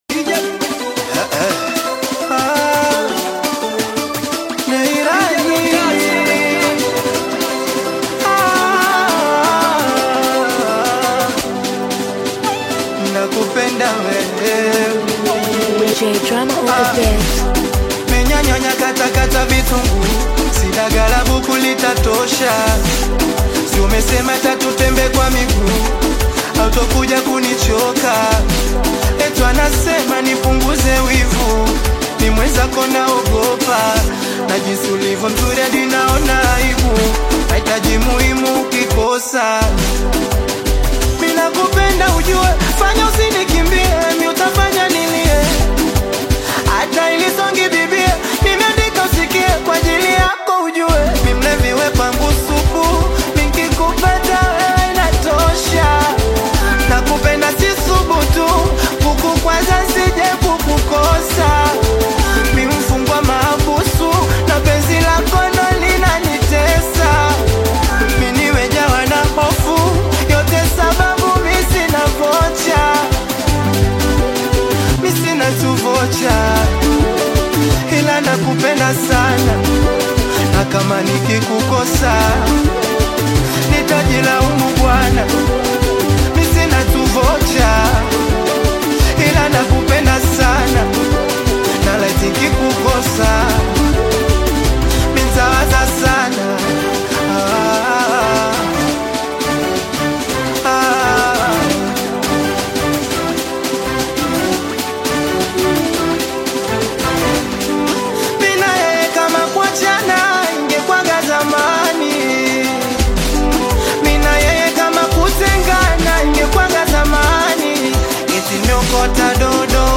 AudioSingeli
Afro-Pop